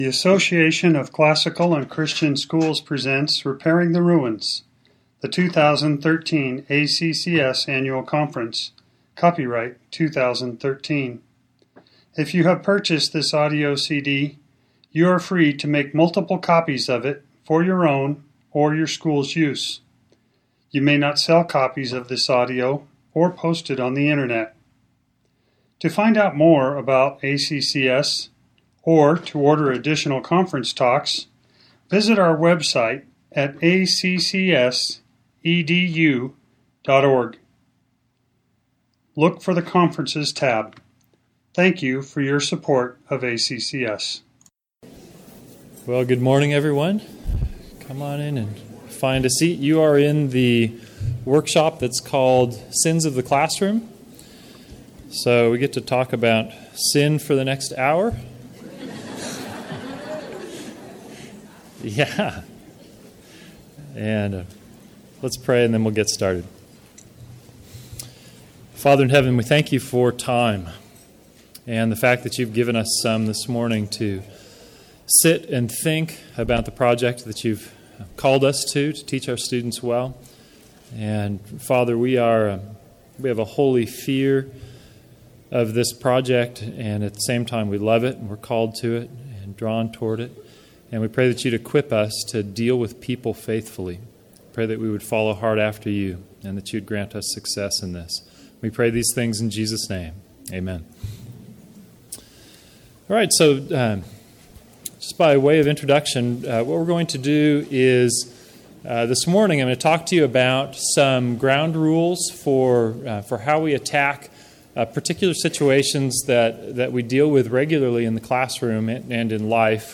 2013 Workshop Talk | 1:06:50 | All Grade Levels, Virtue, Character, Discipline